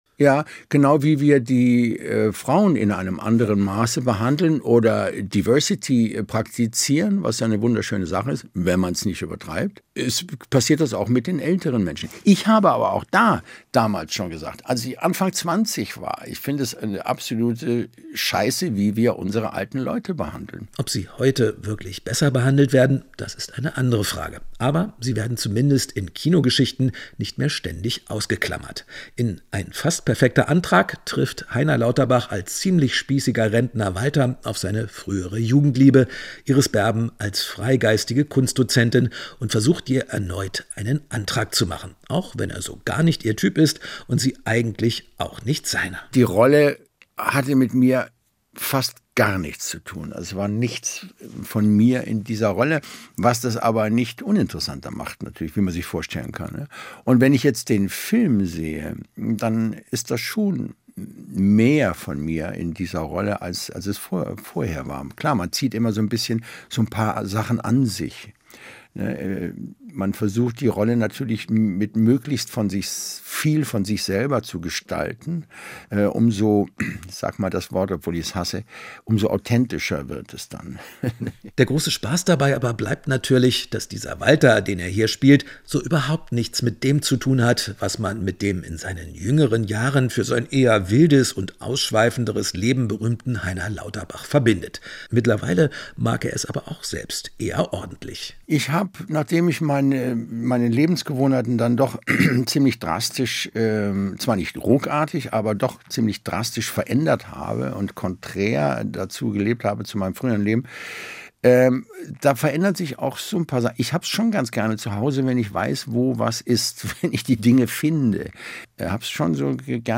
über das Älterwerden gesprochen.